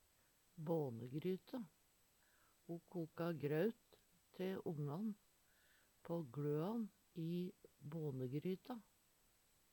bånegryte - Numedalsmål (en-US)